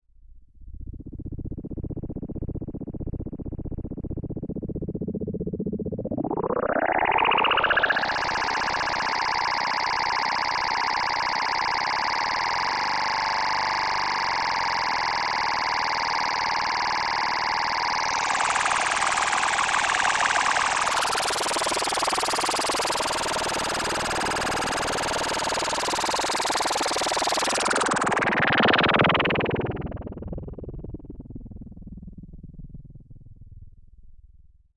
Sci-Fi Ambiences
AFX_ENERGYBEAM_02_DFMG.WAV
Energy Beam 02